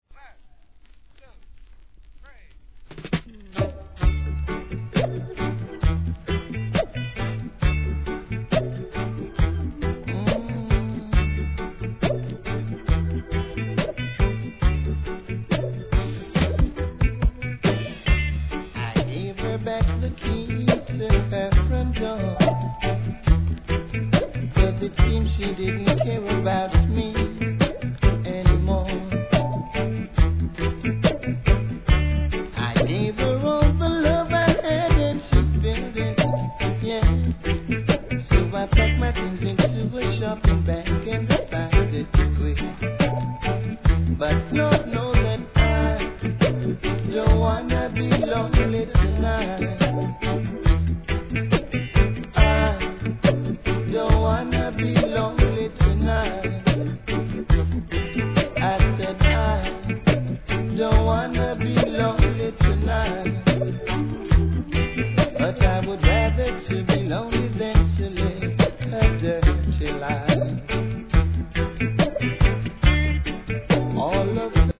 REGGAE
後半はDUB!